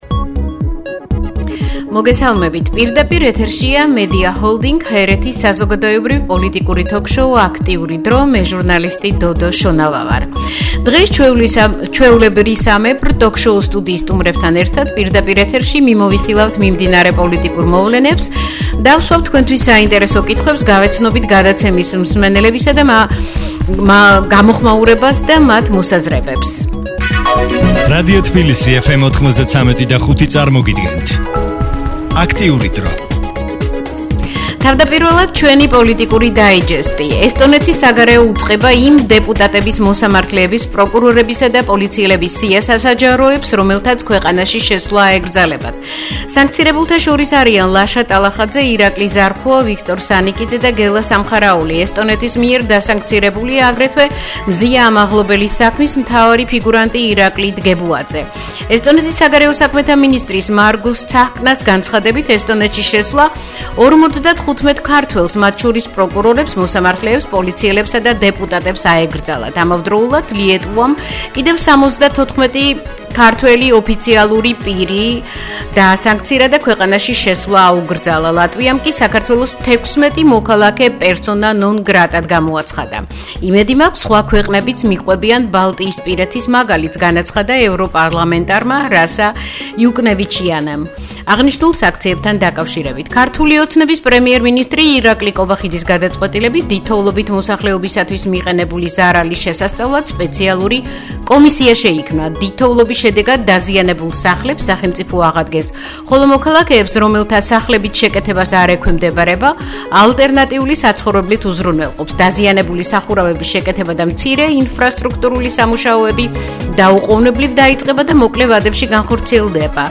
მეორე სტუმარი: ანალიტიკოსი